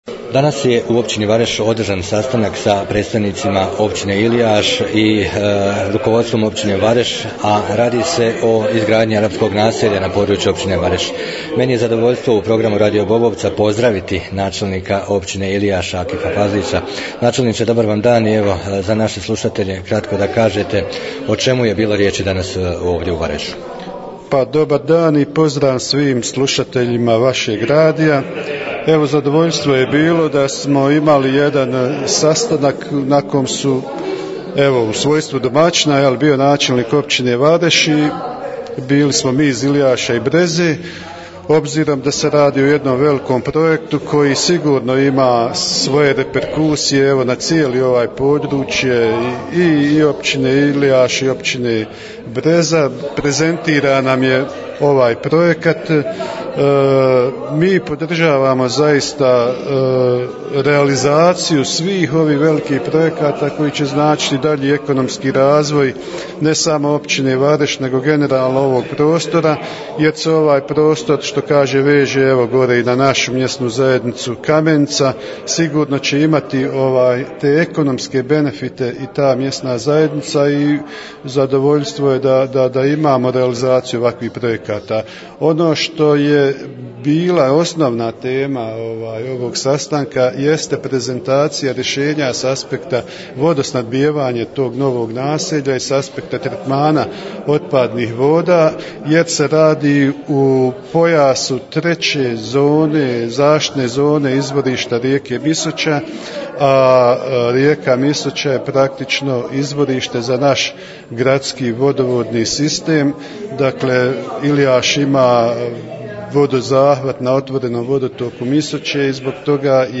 Izjave nakon sastanka načelnika općina Vareš i Ilijaš
U Općini Vareš održan je sastanak s predstavnicima općina Ilijaš i Breza u vezi gradnje naselja na području općine Vareš od strane arapskih investitora. Nakon sastanka dobili smo izjave načelnika Vareša Zdravka Maroševića i Ilijaša Akifa Fazlića, poslušajte …..